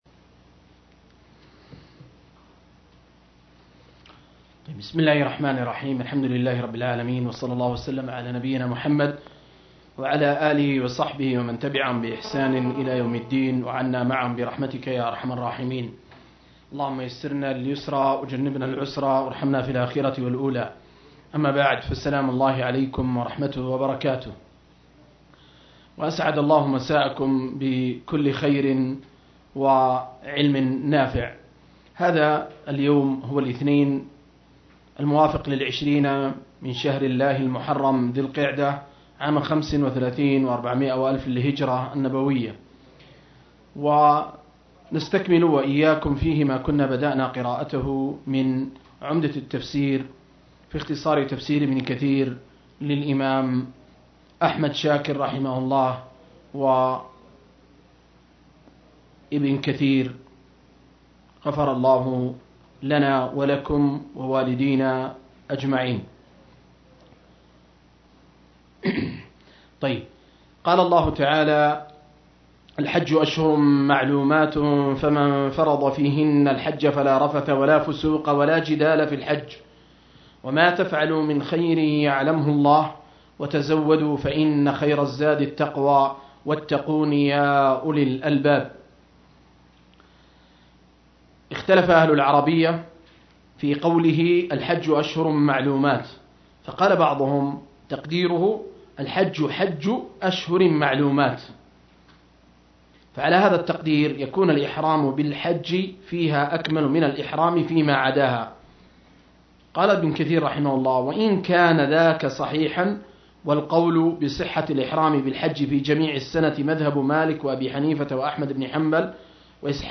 040- عمدة التفسير عن الحافظ ابن كثير رحمه الله للعلامة أحمد شاكر رحمه الله – قراءة وتعليق –